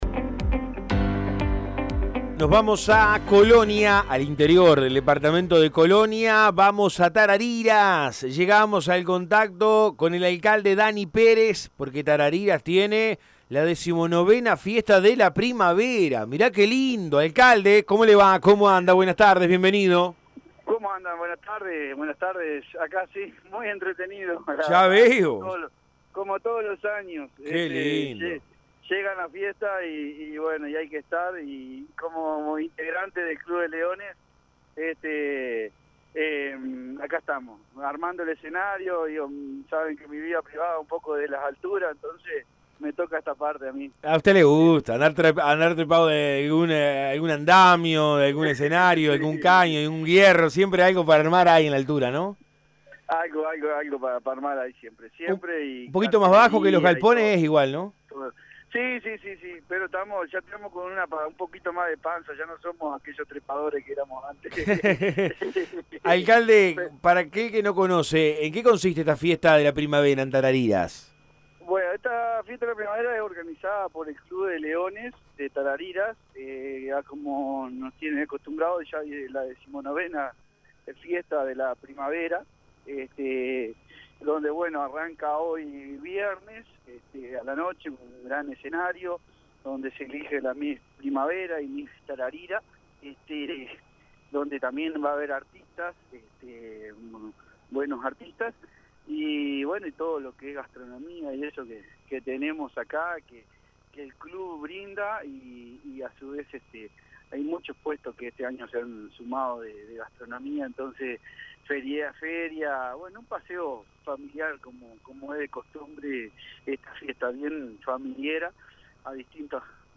Dany Pérez alcalde de Tarariras habló en Todo Un País donde repasó la grilla musical y contó las actividades que se llevarán a cabo durante el fin de semana.